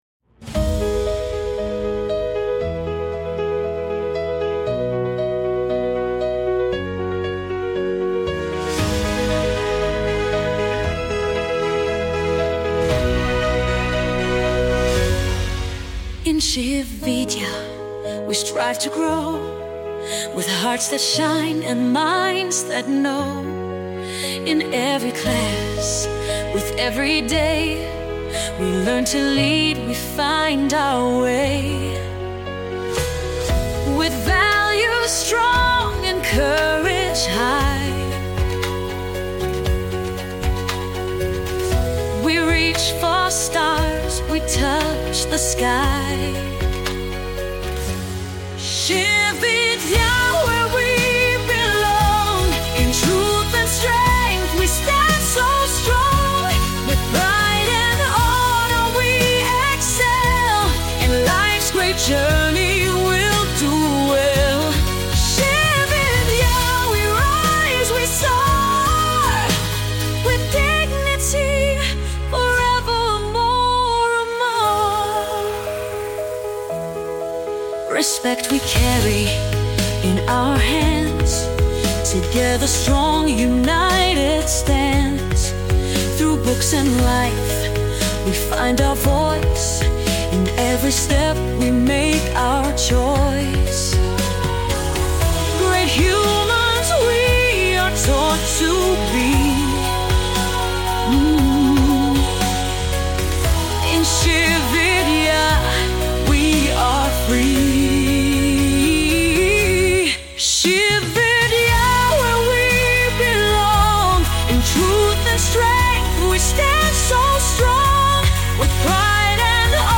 School Anthem
school-anthem.mp3